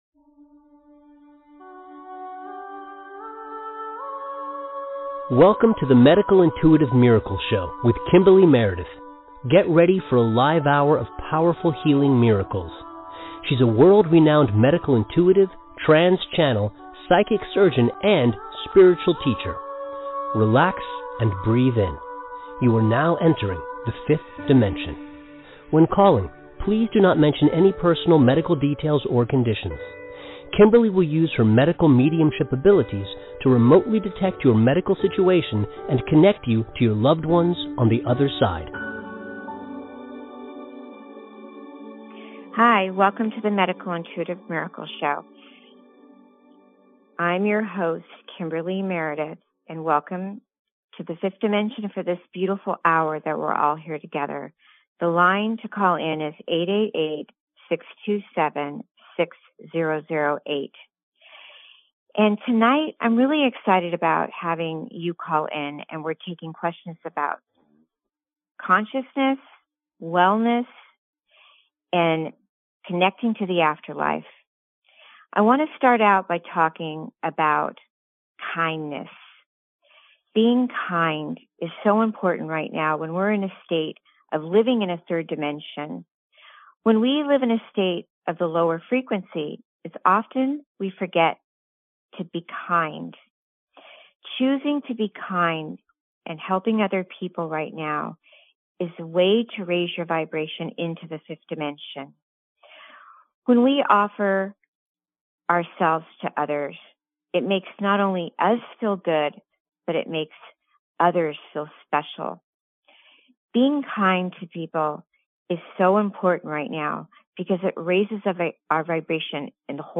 Debut show on BBS Radio TV - Taking lots of caller questions & providing intuitive, spiritual guidance from your guides